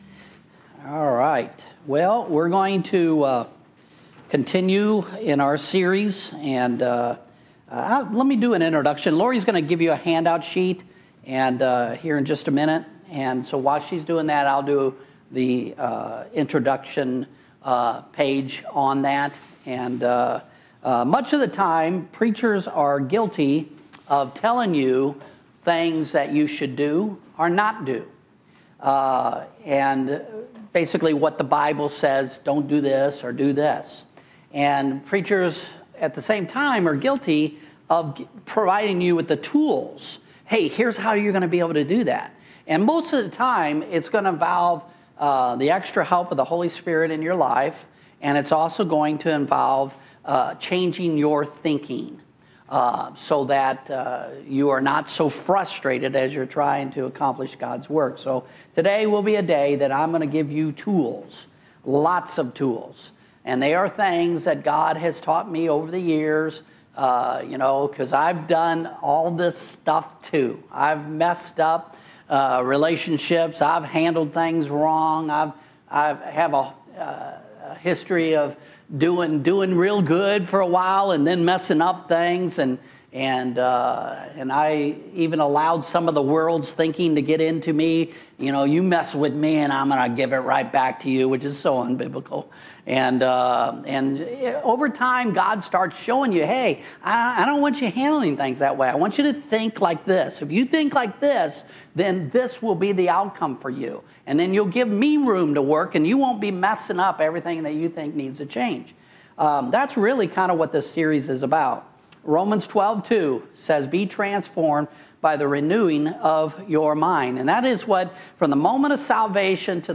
Sunday Morning Worship Service